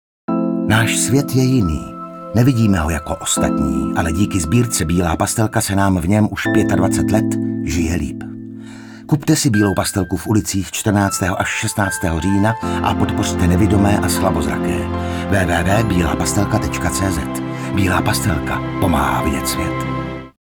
Mediální kampaň 2024, Bílá pastelka - pomáhá vidět svět, také letos již potřetí promlouvá hlasem herce Michala Dlouhého, a na obrazovkách ji lidé uvidí začátkem října ve vysílání České televize.